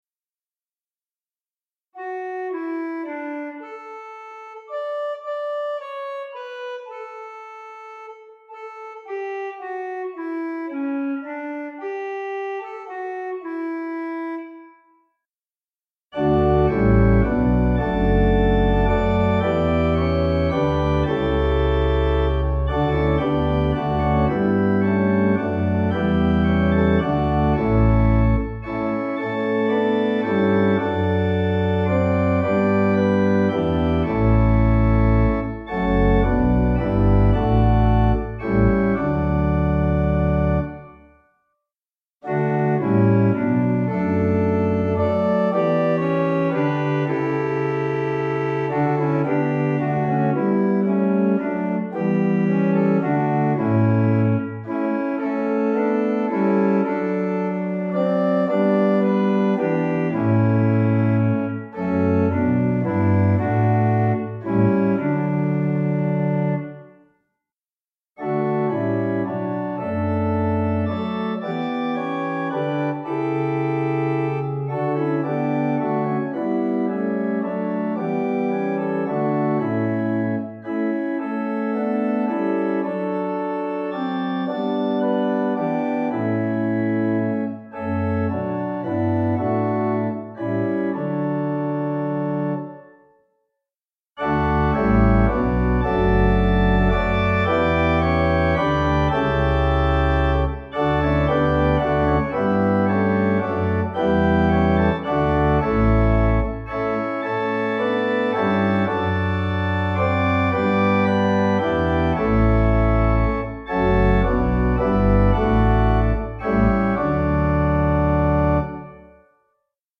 Organ: Little Waldingfield